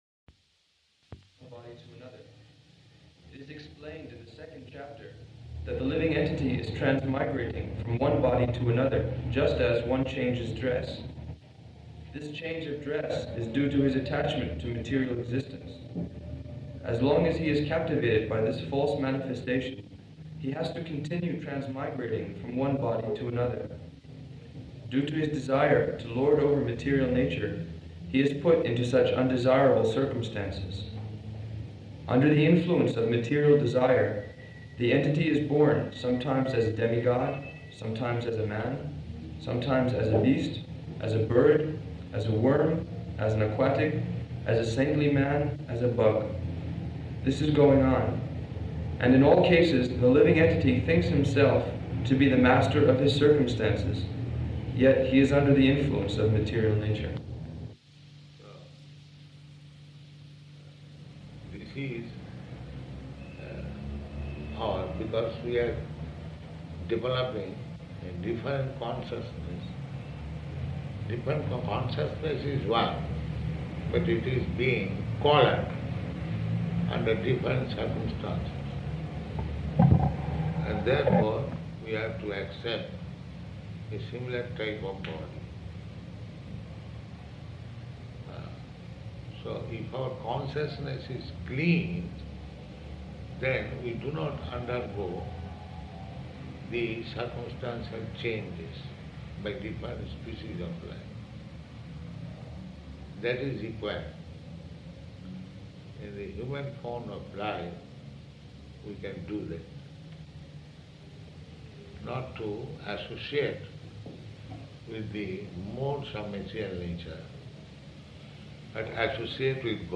Type: Conversation
Location: Rome